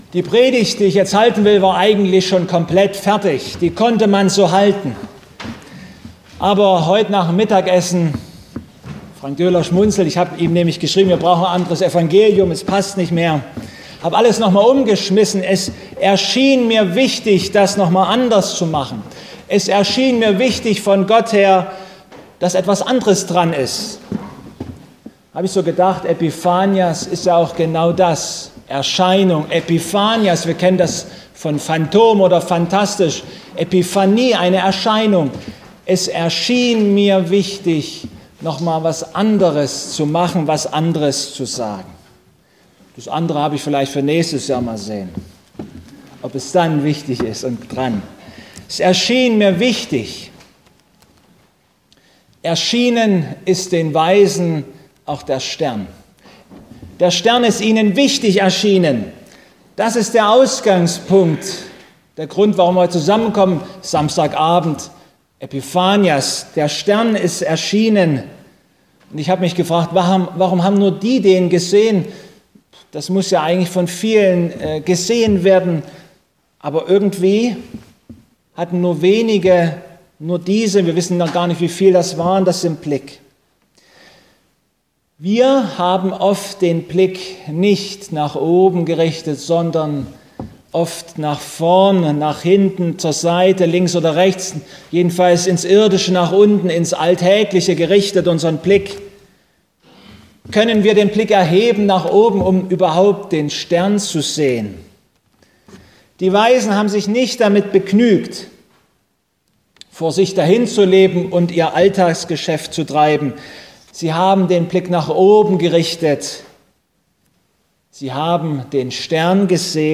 Zu Epiphanias - Erscheinungsfest - Dreikönigstag ... feiern wir einen gemeinsamen musikalischen Gottesdienst, wo ganz viele unterschiedliche Gruppen aus unseren 3 Schwestergemeinden beteiligt sind.